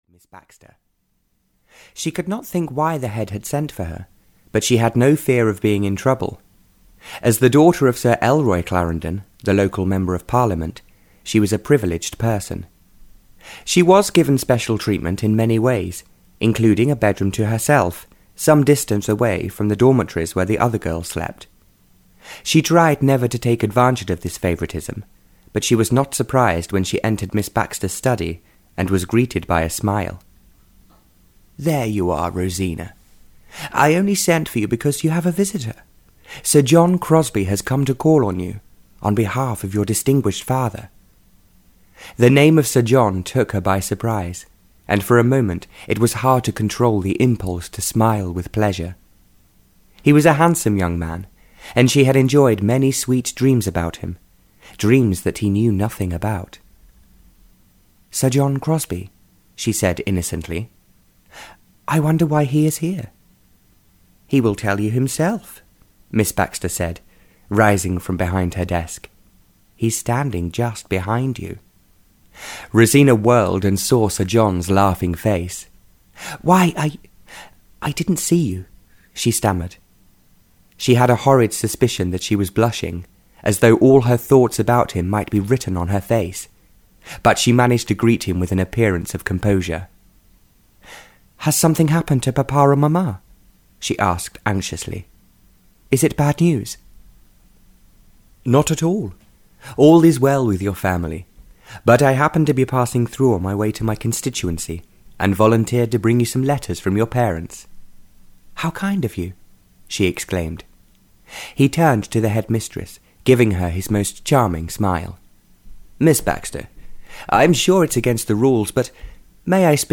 Love is Triumphant (Barbara Cartland’s Pink Collection 5) (EN) audiokniha
Ukázka z knihy